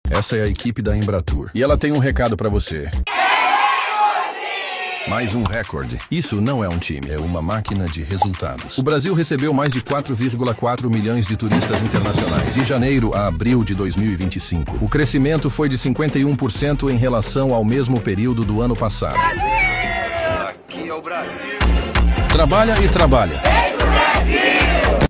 SPOT | O mundo todo quer vir ao Brasil!